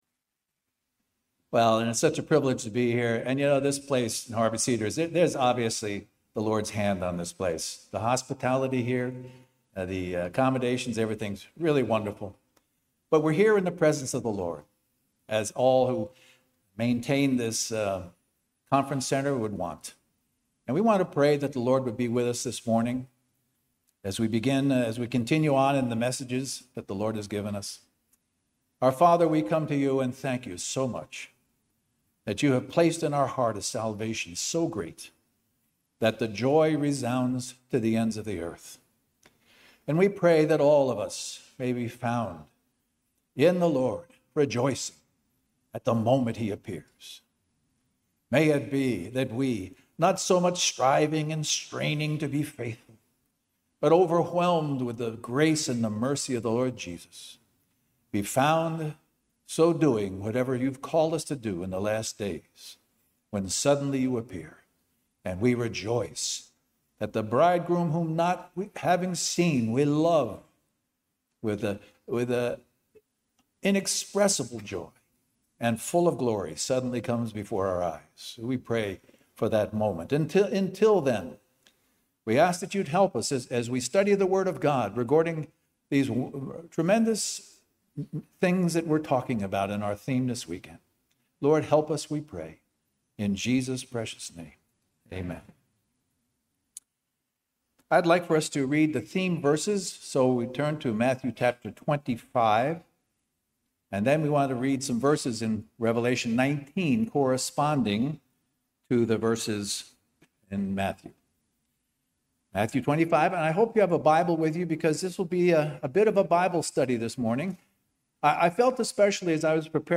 Harvey Cedars Conference